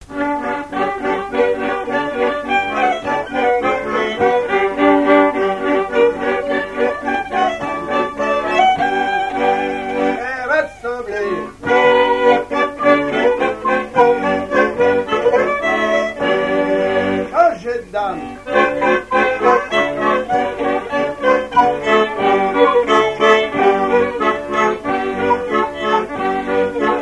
danse : quadrille : galop
Pièce musicale inédite